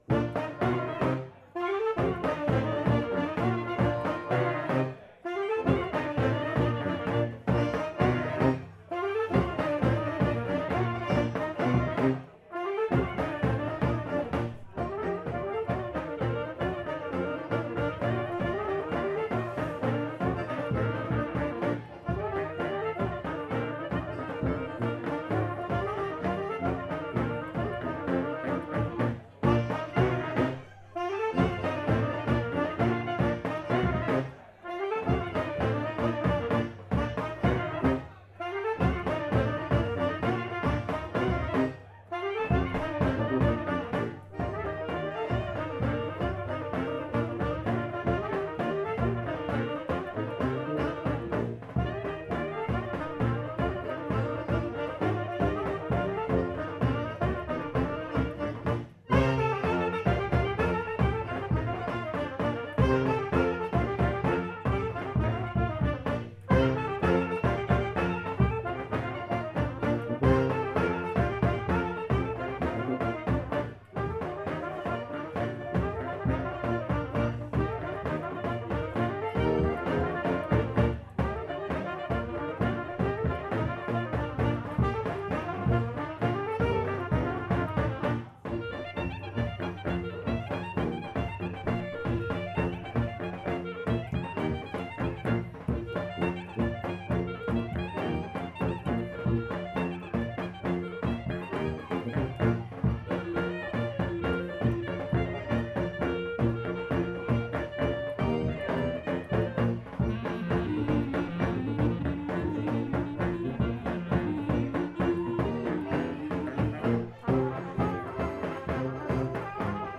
Gig Recordings Live in Fresno